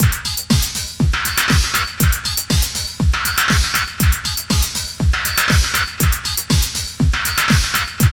15.5 LOOP2.wav